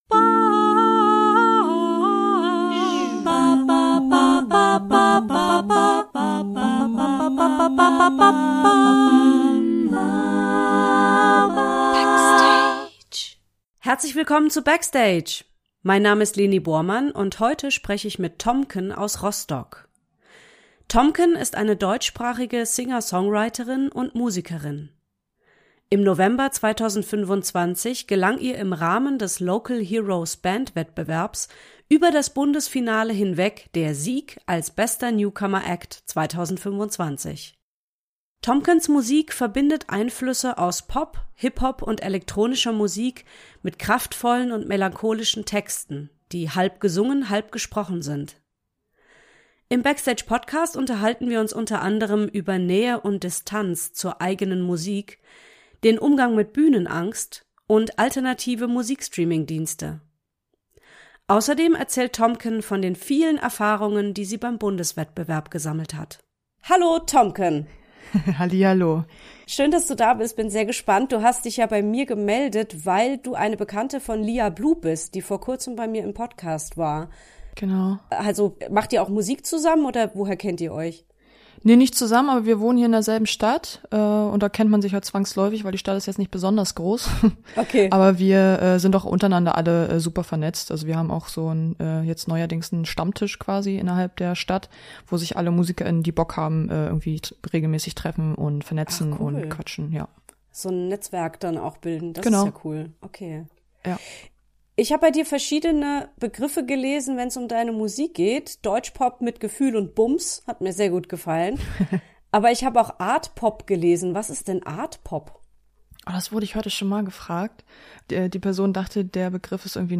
Im Backstage Podcast unterhalten wir uns unter anderem über Nähe und Distanz zur eigenen Musik, den Umgang mit Bühnenangst und alternative Musikstreaming-Dienste.